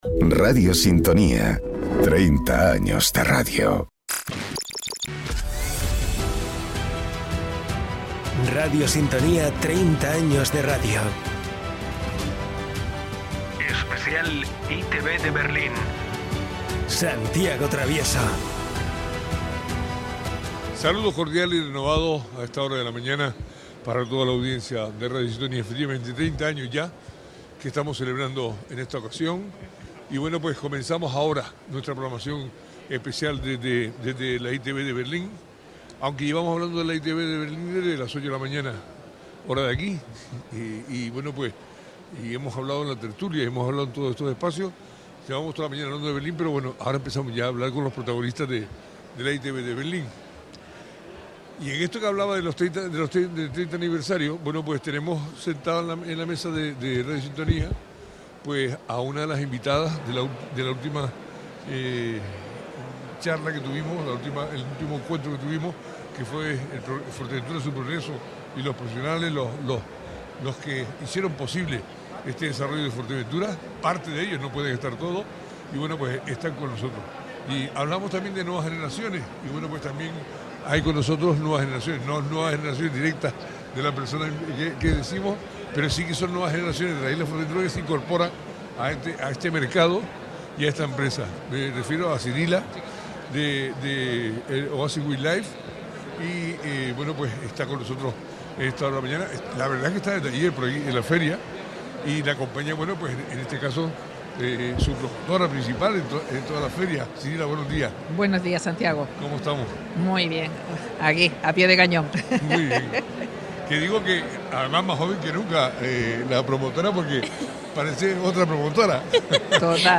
en el especial que Radio Sintonía realiza desde el Pabellón de Canarias en la Bolsa Internacional de Turismo de Berlín.